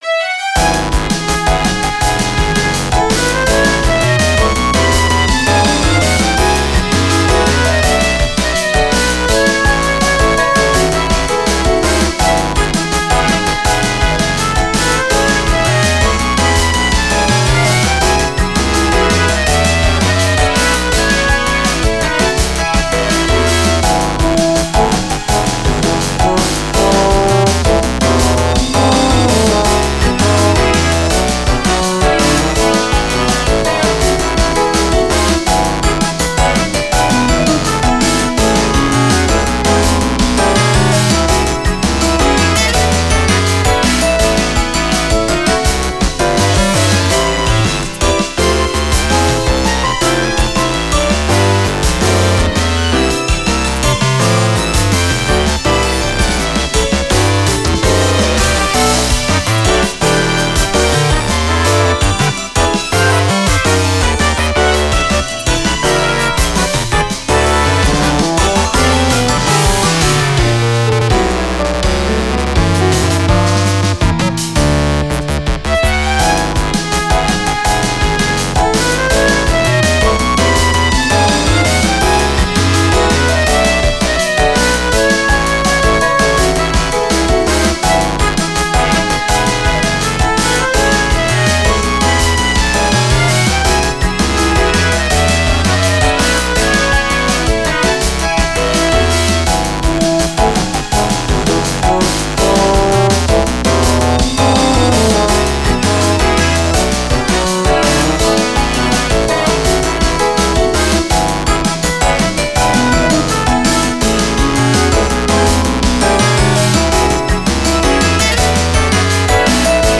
明るい雰囲気の曲です。
ベースが元気です
タグ あかるい 朝